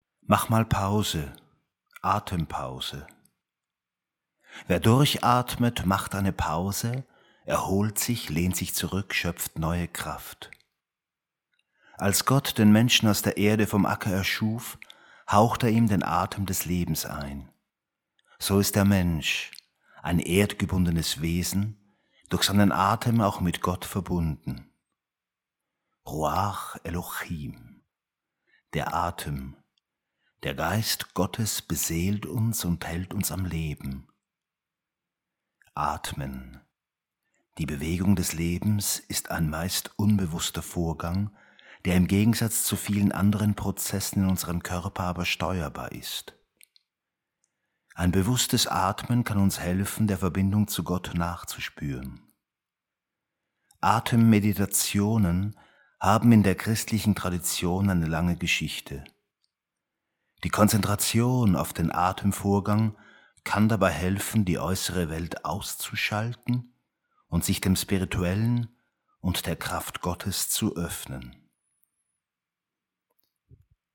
Motiv des Atems in der Bibel zum Lesen und zum Hören Teil 1 und Teil 2 .